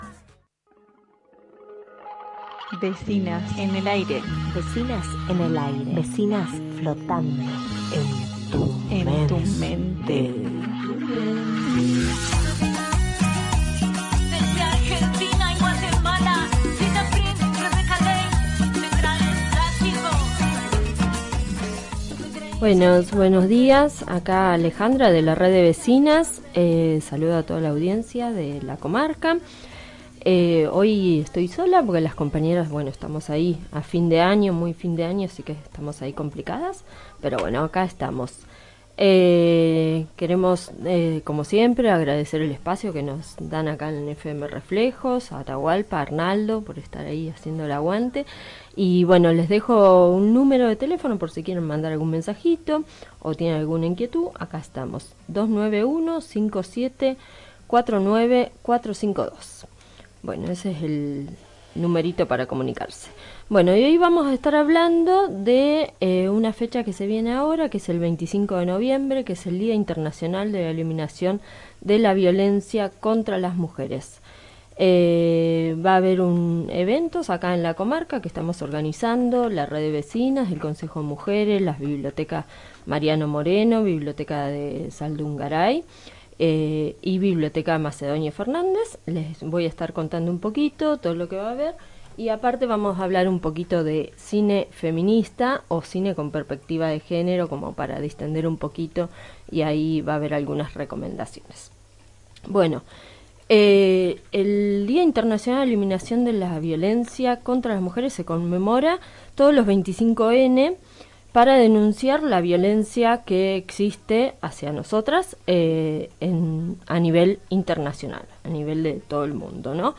Columna radial de los martes , la red de vecinas de sierra de la ventana informamos sobre el 25 de Noviembre: Día Internacional de la Eliminación de la Violencia contra las Mujeres.